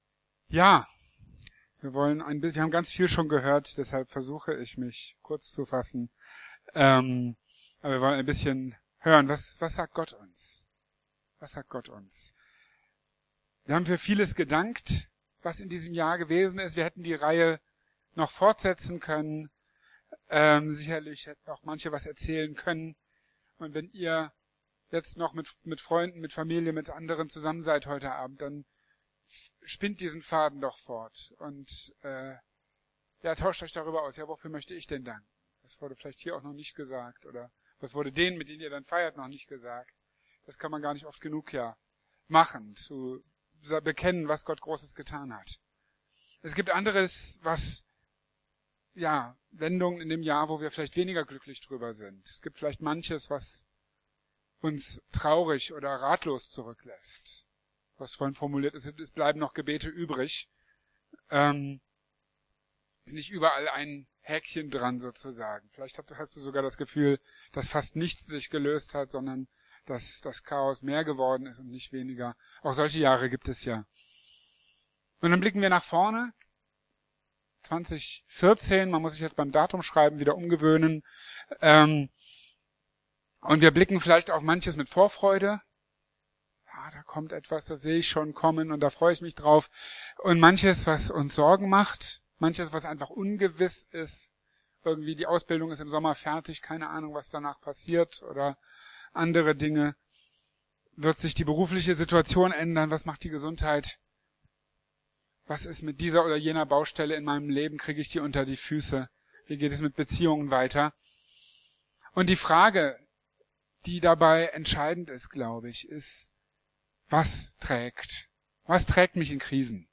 | Marburger Predigten